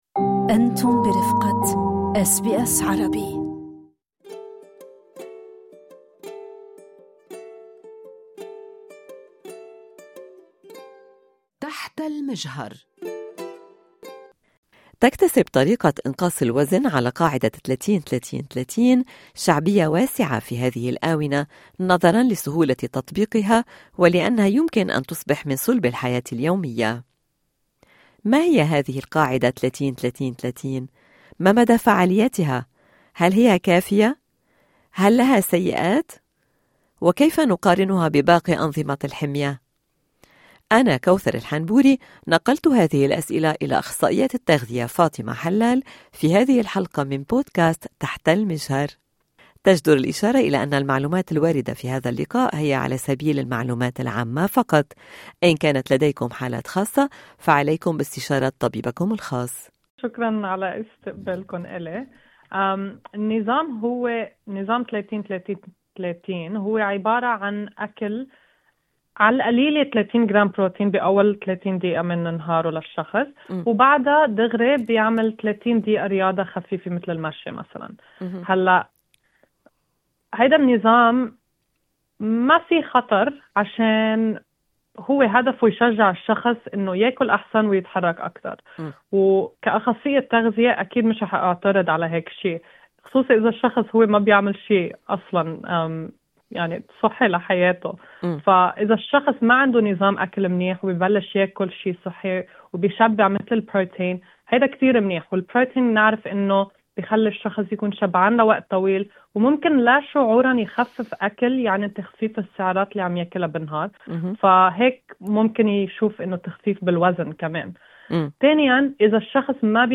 اشارة الى أن هذا اللقاء يقدم معلومات عامة فقط لمزيد من التفاصيل عن حالات خاصة عليكم باستشارة طبيبكم الخاص